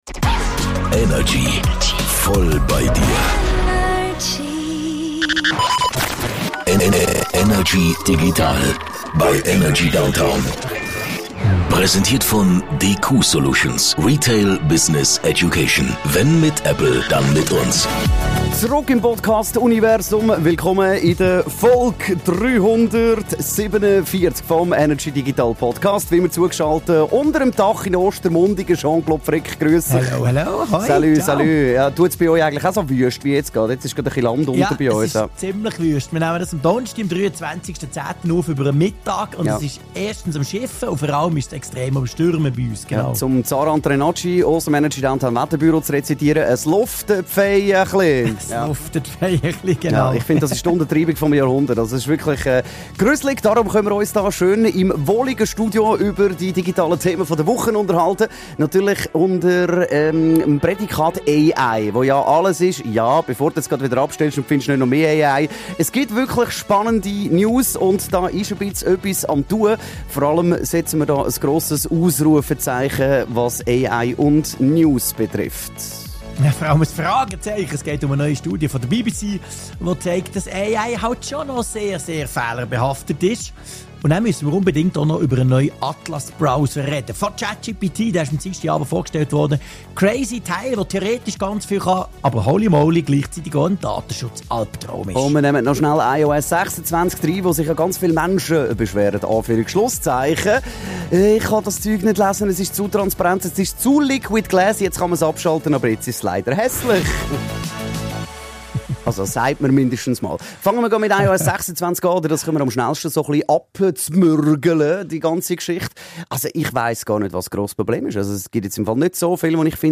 im Energy Studio
aus dem HomeOffice über die digitalen Themen der Woche.